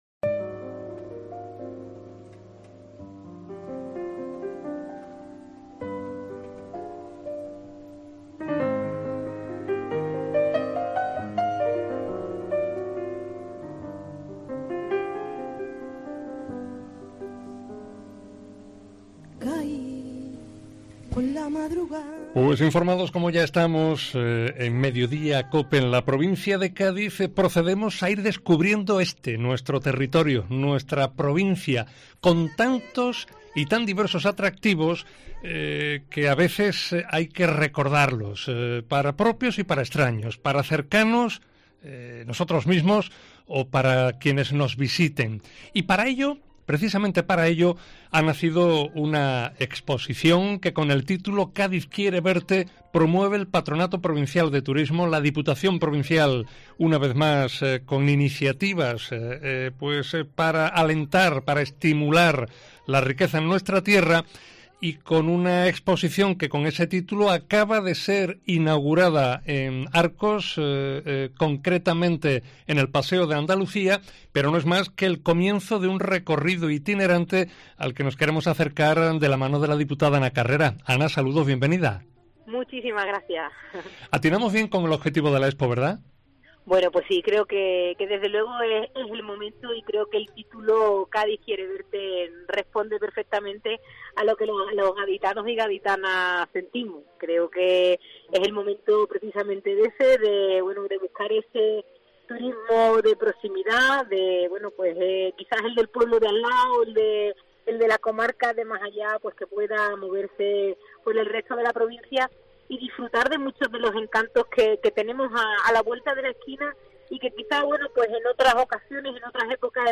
AUDIO: Entrevista Ana Carrera, diputada de la Diputación de Cádiz, sobre el proyecto 'Cádiz quiere verte'